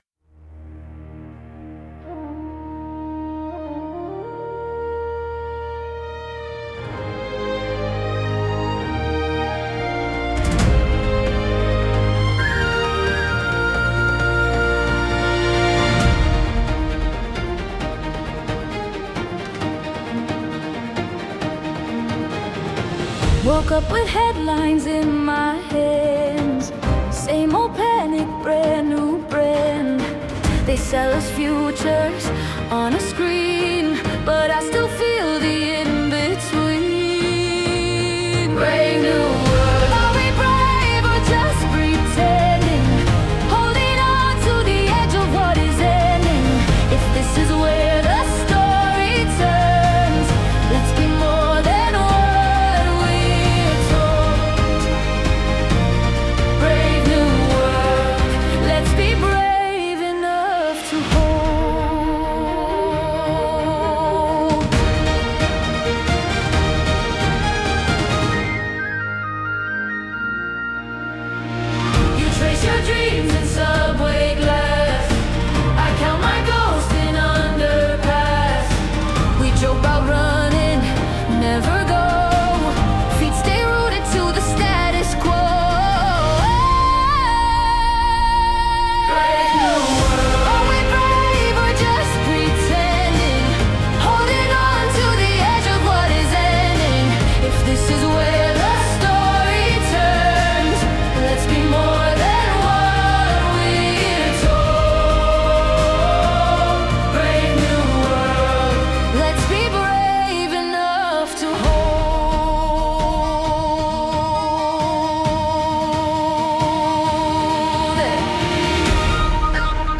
Instrumental / 歌なし
まるでディズニー映画のクライマックスを見ているような、希望と感動に満ちたシネマティック・サウンド！
美しいケルトフルートの音色と、空高く舞い上がるようなストリングスが、聴く人の心をポジティブなエネルギーで満たします。